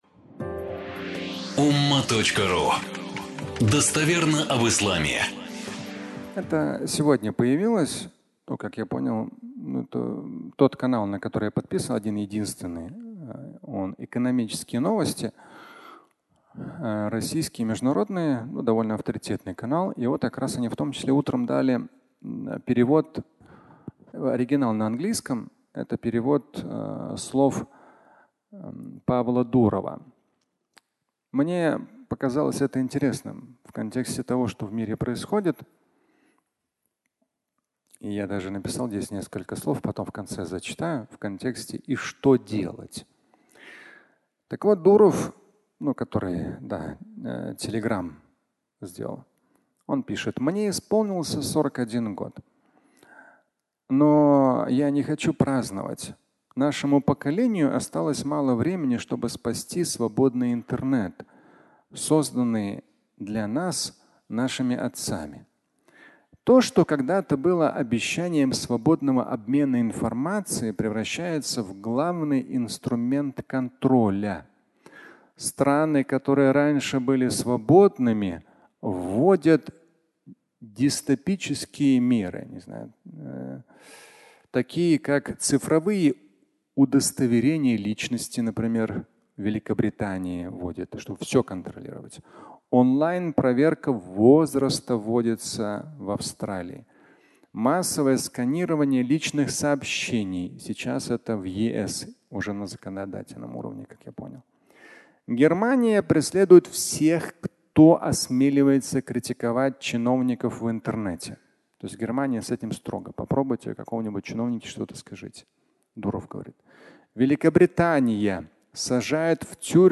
Свобода или тюрьма Интернета (аудиолекция)